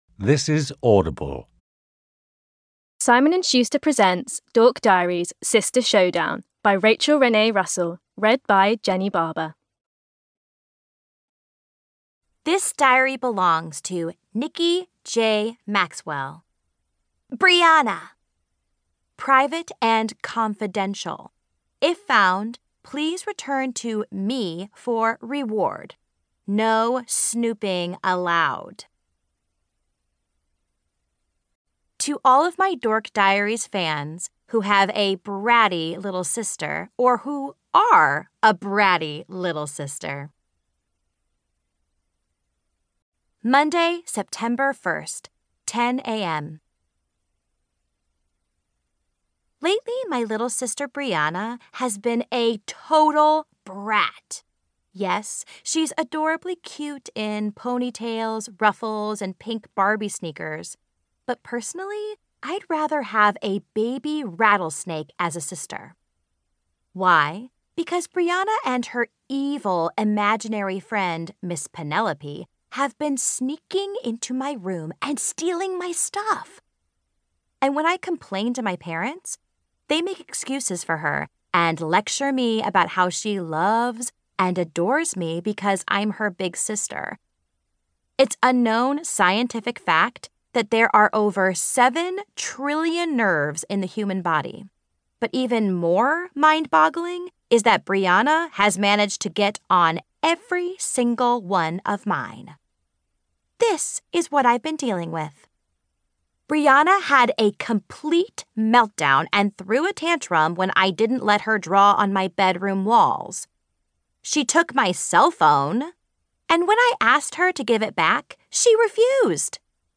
Rachel Renee Russell - Dork Diaries: Sister Showdown Audiobook (Book 16) - Goldenaudiobooks